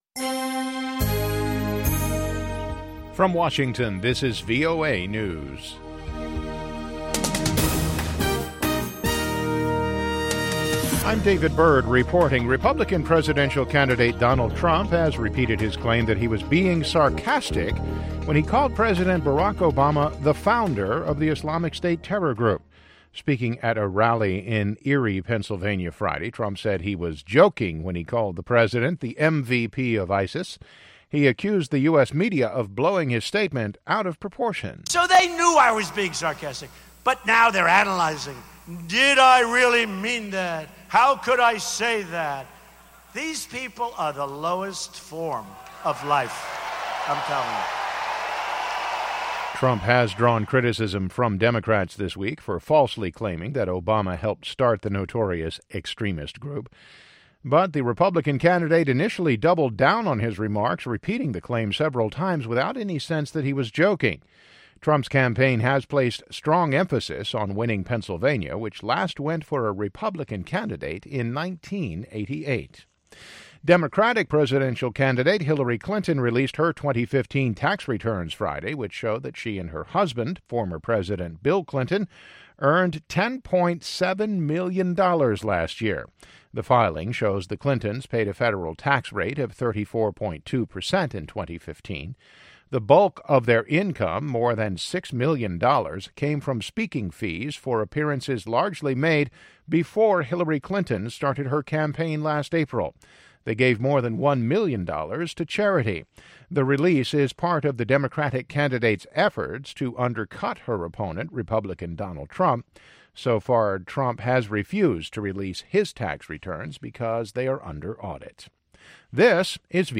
اخبار